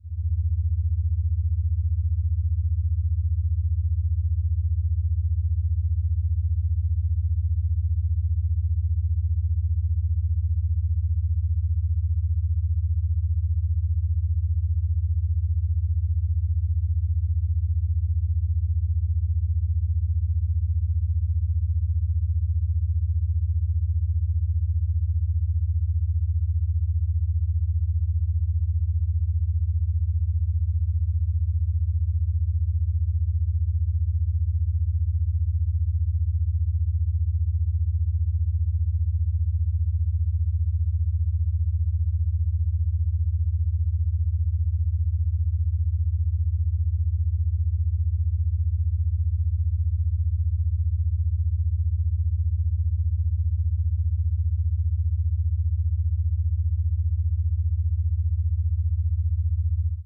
75Hz Monaural (15Hz  Beta Waves) .wav